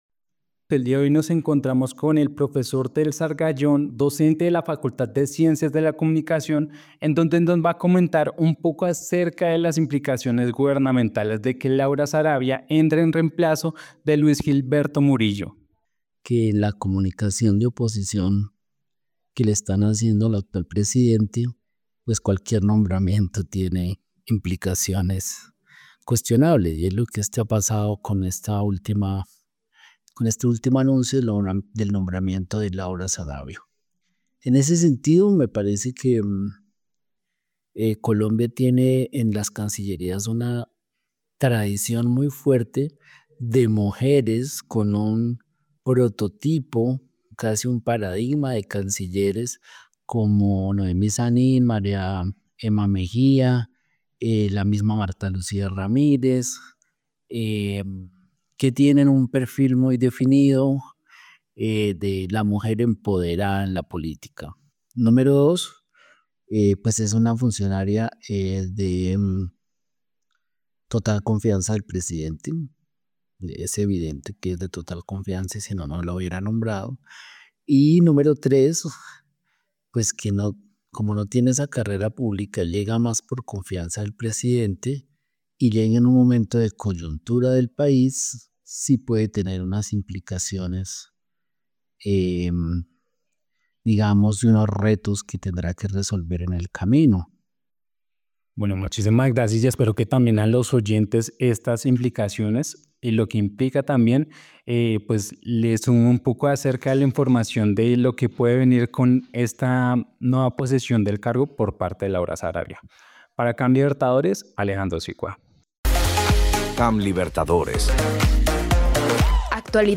Con la posesión del nuevo cargo de Laura Sarabia como nueva canciller, llegan nuevos retos, en la siguiente entrevista te contamos sobre ello: